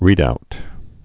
(rēdout)